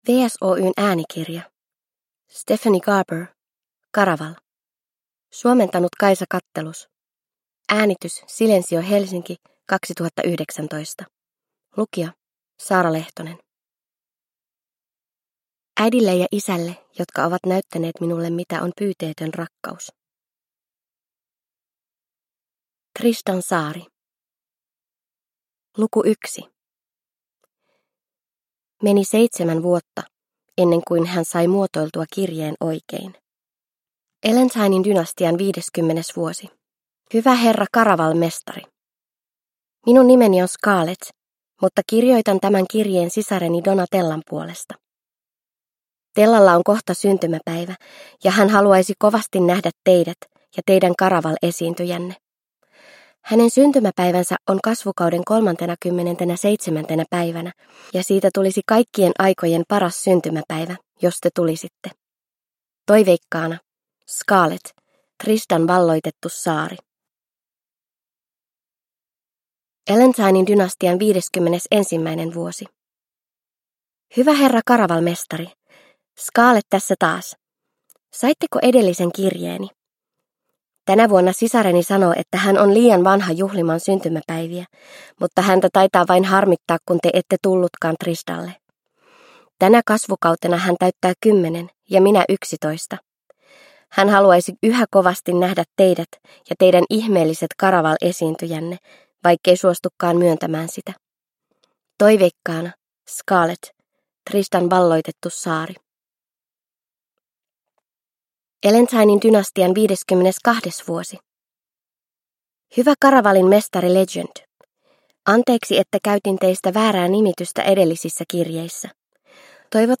Caraval – Ljudbok – Laddas ner